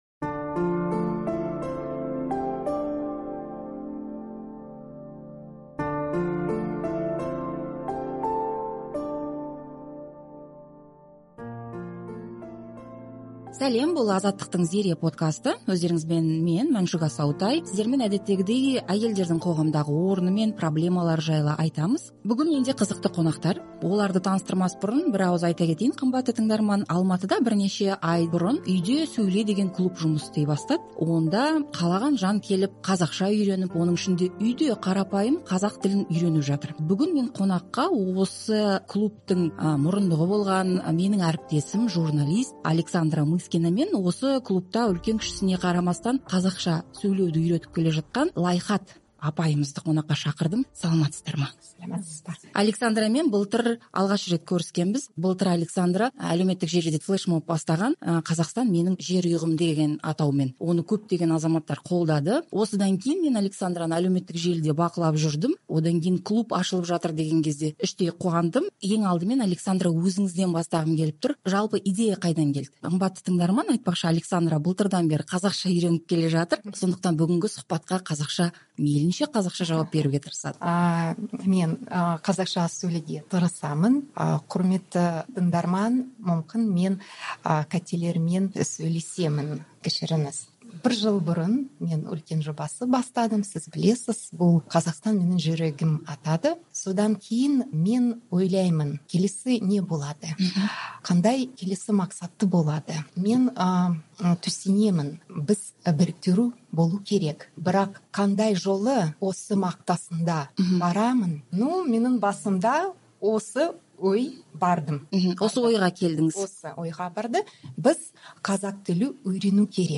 Азаттықтың "Зере" подкасының бүгінгі эпизоды Алматыдағы көпқабатты үйдің жертөлесінде орналасқан "Үйде сөйле" клубына арналды. Шағын бөлме.